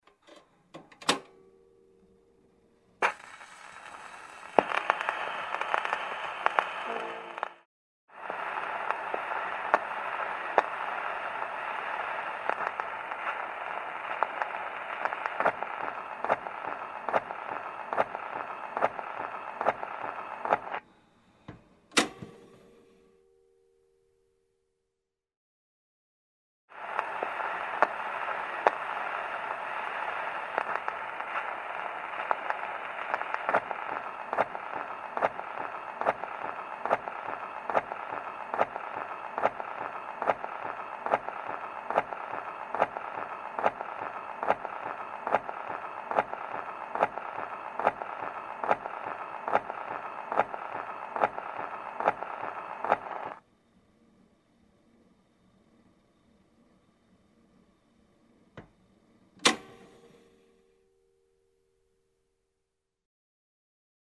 Граммофон включается шум пластинки конец записи